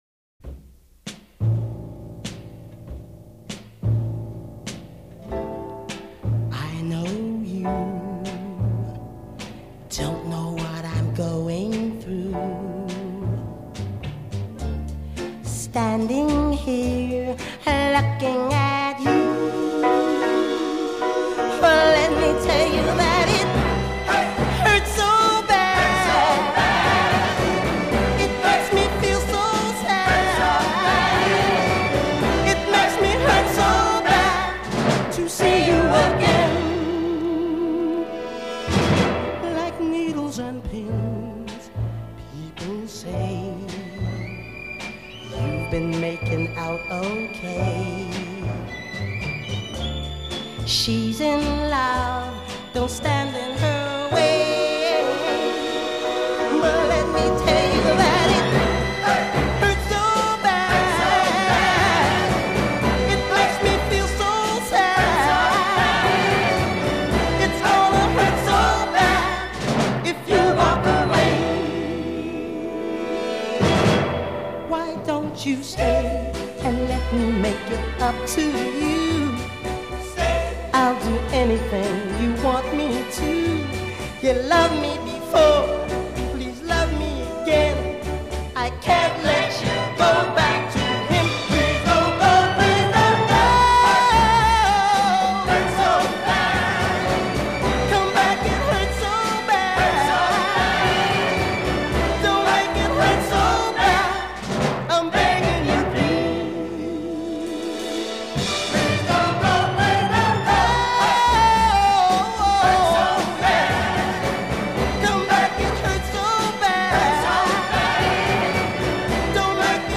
ballad
soaring tenor